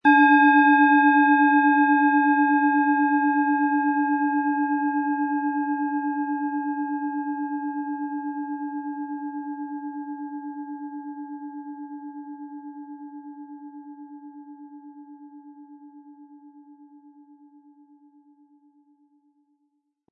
Planetenschale® Kraftvoll und Aktiv fühlen & Angeregt fühlen mit Mars, Ø 12 cm, 180-260 Gramm inkl. Klöppel
Sie möchten den schönen Klang dieser Schale hören? Spielen Sie bitte den Originalklang im Sound-Player - Jetzt reinhören ab.
Aber uns würde der kraftvolle Klang und diese außerordentliche Klangschwingung der überlieferten Fertigung fehlen.
Mit Klöppel, den Sie umsonst erhalten, er lässt die Planeten-Klangschale Mars voll und harmonisch erklingen.
PlanetentonMars
MaterialBronze